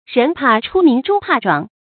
人怕出名豬怕壯 注音： ㄖㄣˊ ㄆㄚˋ ㄔㄨ ㄇㄧㄥˊ ㄓㄨ ㄆㄚˋ ㄓㄨㄤˋ 讀音讀法： 意思解釋： 人怕出了名招致麻煩，就象豬長肥了就要被宰殺一樣。